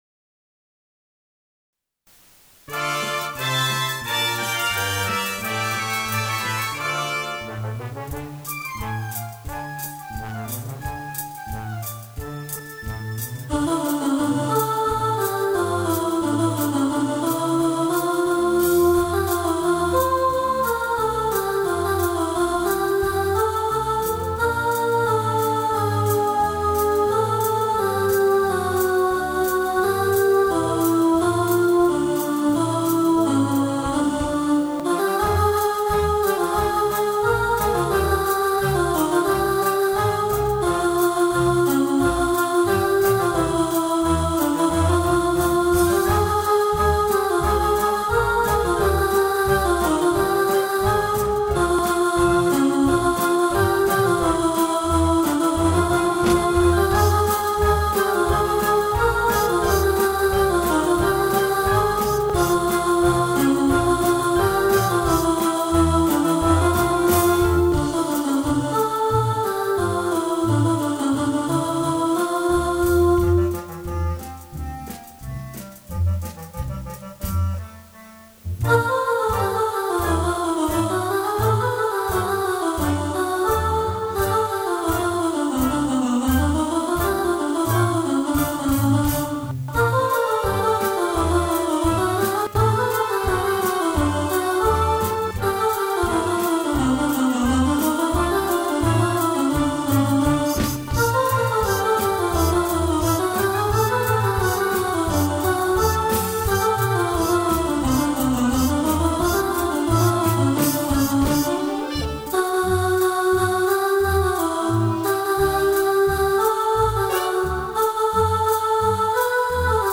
Rhythm Of Life – Alto | Ipswich Hospital Community Choir
Rhythm-Of-Life-Alto.mp3